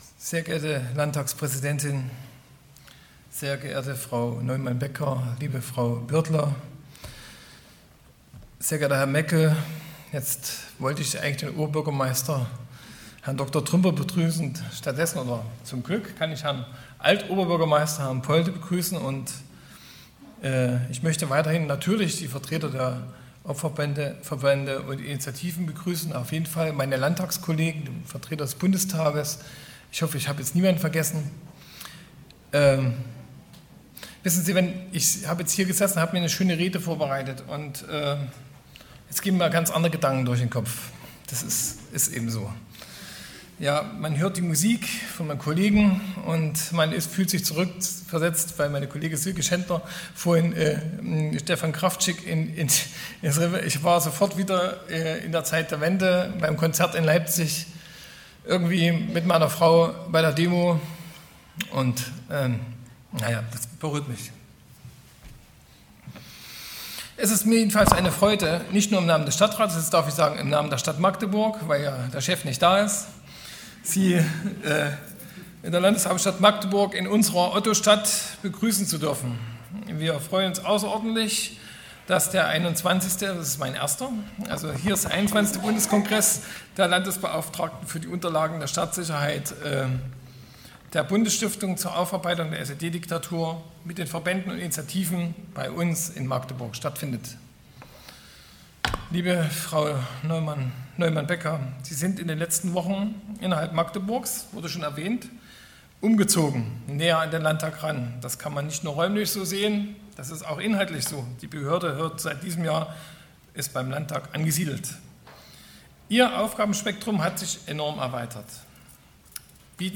Audiodokumentation Bundeskongress 2017: „Erinnern und Zeichen setzen! – Zeugnisse politischer Verfolgung und ihre Botschaft.“ 28./29./30. April 2017, Magdeburg, Maritim Hotel (Teil 1: 28. April)
Grußwort Andreas Schumann MdL